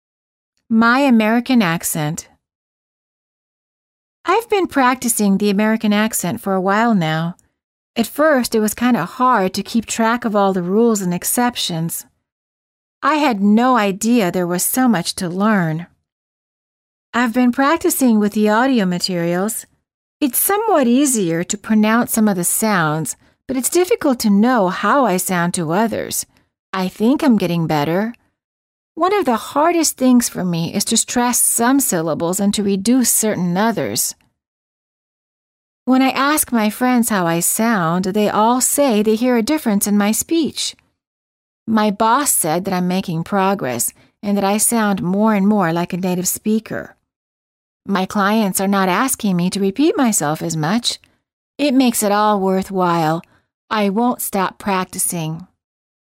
• 発音するときに一度にまとめて発音し、Thought groupとThought groupの間はポーズを入れて発音する
※当メディアは、別途記載のない限りアメリカ英語の発音を基本としています